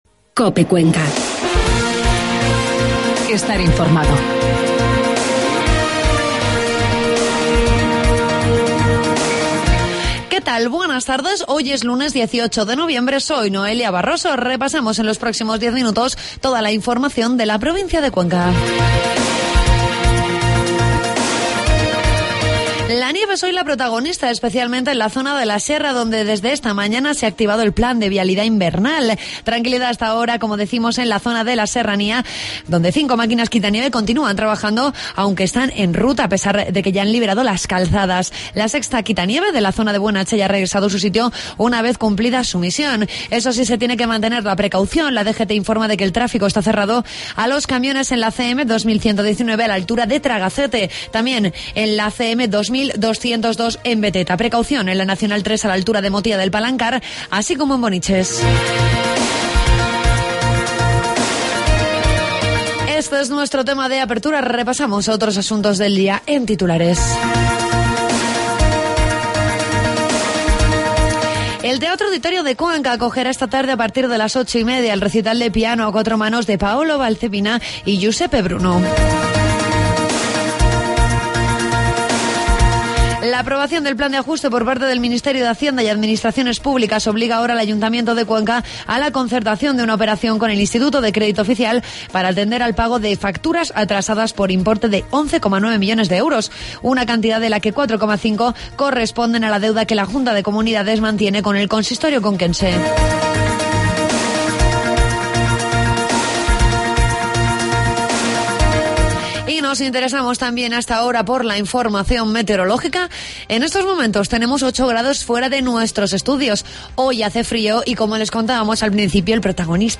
AUDIO: Toda la información de la provincia de Cuenca en los informativos de mediodía de COPE.